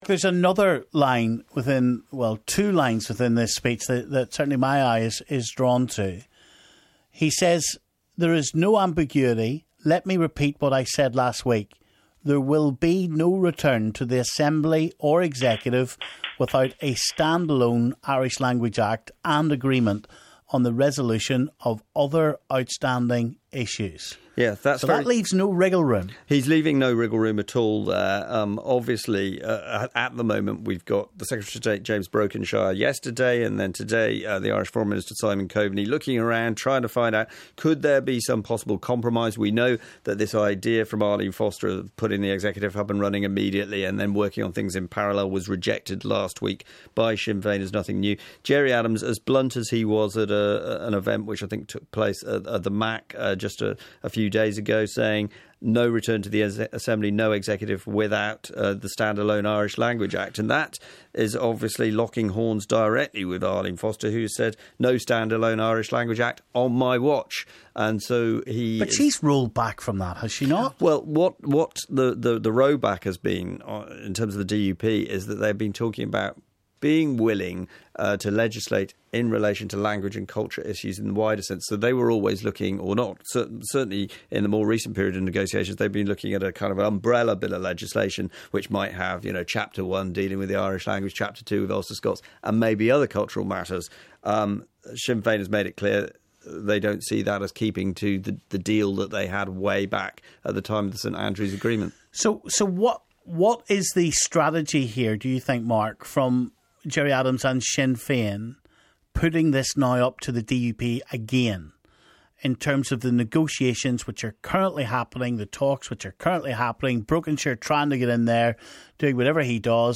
Adams statement fires Irish Language Act warning - commentators react